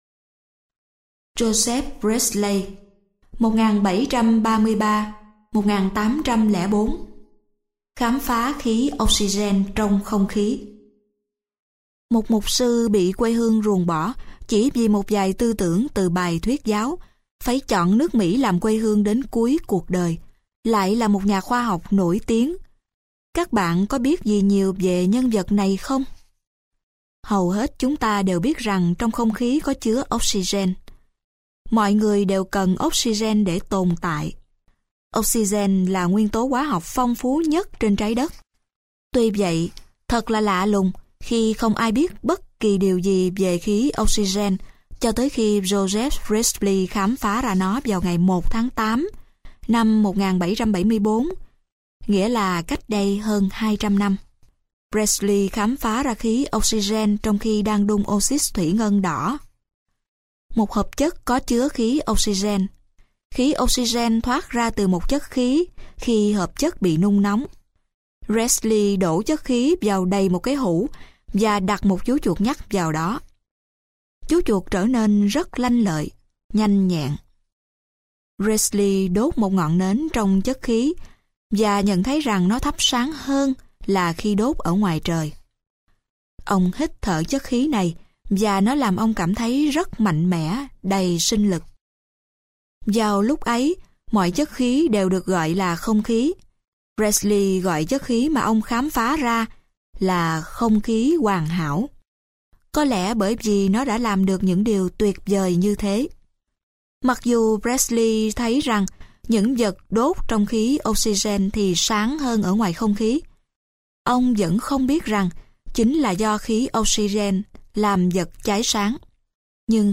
Sách nói Các Nhà Khoa Học Và Những Phát Minh - Sách Nói Online Hay